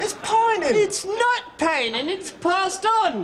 Phonetically, he has the refayned combination of Popular London features (h-drop, glottaling, –ing as [ɪn], etc.) with the hypercorrected PRICE vowel.
When the shopkeeper (Michael Palin, using un-refayned Popular London) claims repeatedly that the “Norwegian Blue” parrot is not dead but pining [pɑjnɪn] for the fjords, the exasperated customer echoes this as [pɛjnɪn]:
refayned_pining.mp3